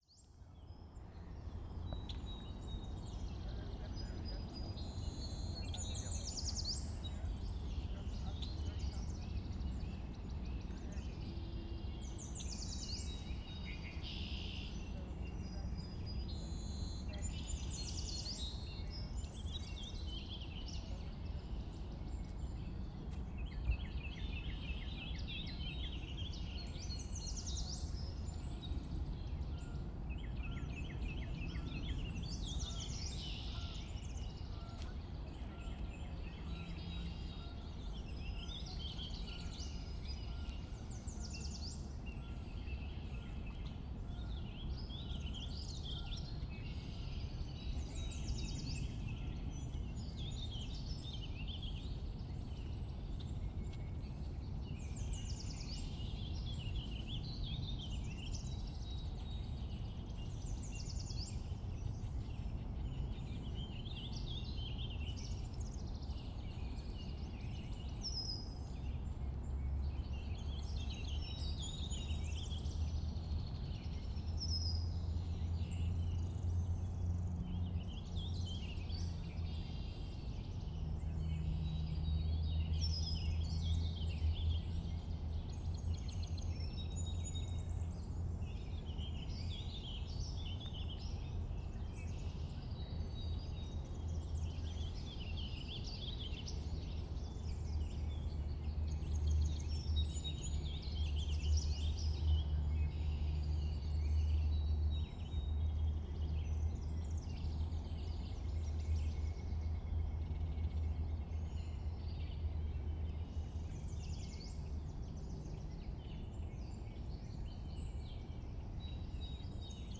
birds – surburban 2
birds-surburban-2.mp3